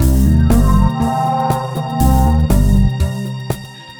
Under Cover (Full) 120BPM.wav